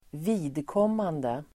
Ladda ner uttalet
vidkommande substantiv, Uttal: [²v'i:dkåm:ande] Synonymer: gällande, relevantIdiom: för mitt (el.
vidkommande.mp3